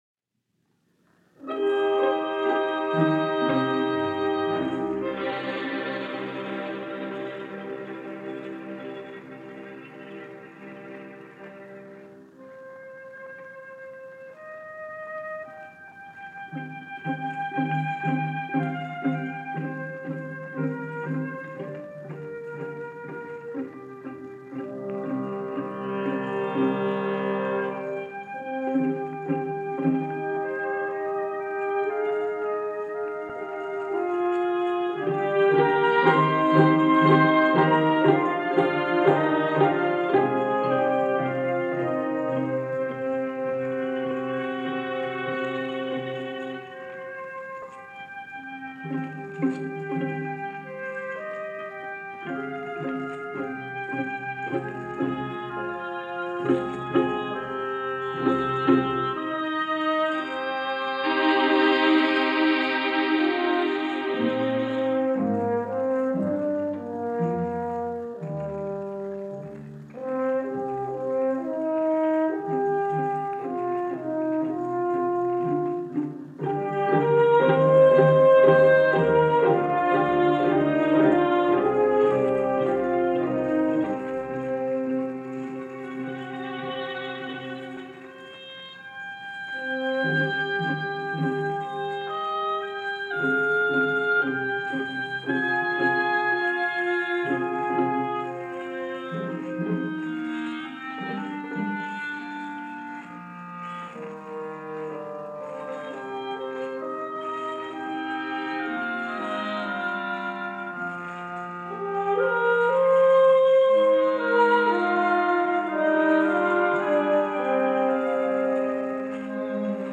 But in the meantime, this may be the only known recording of his work, and the first performance at that. Conducted by Howard Hanson, here is the John Jacob Niles Suite by Weldon Hart, from a broadcast by the Eastman School Symphony of May 14, 1949.